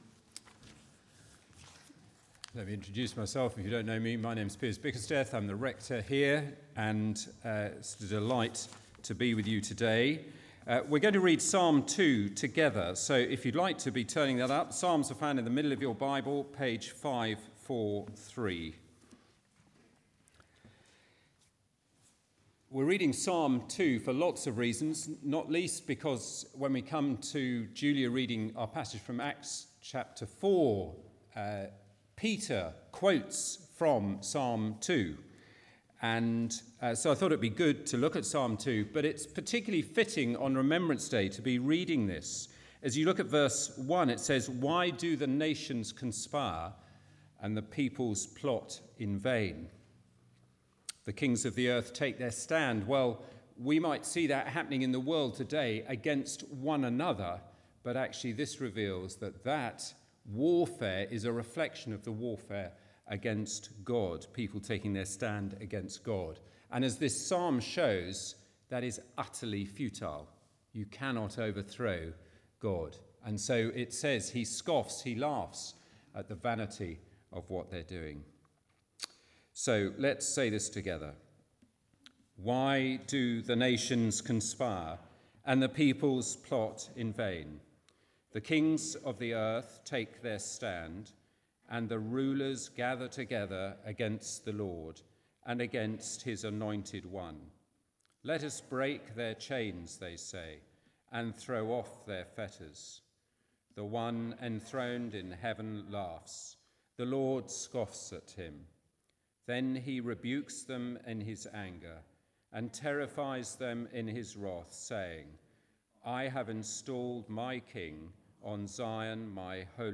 Media for Arborfield Morning Service on Sun 13th Nov 2022 10:00
Theme: Sermon